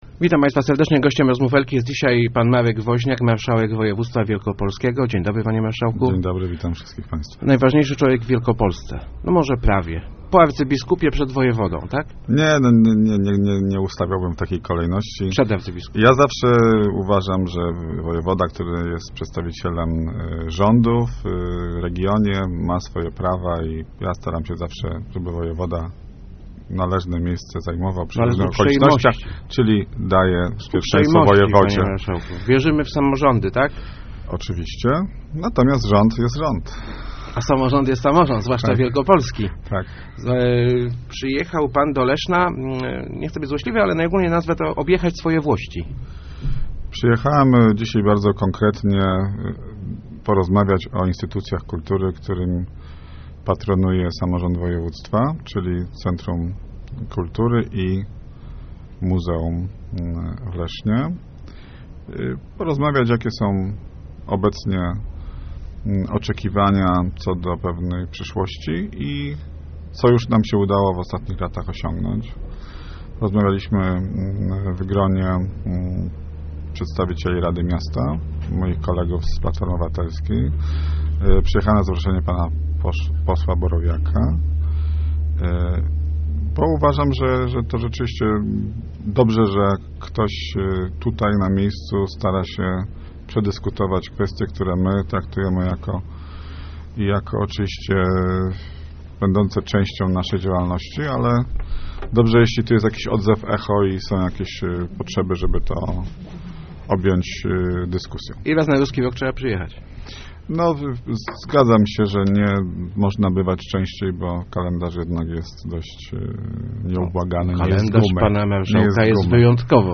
Jeste�my bardzo zadowoleni z funkcjonowania Centrum Kultury i Sztuki - mówi� w Rozmowach Elki Marek Wo�niak, marsza�ek Województwa Wielkopolskiego.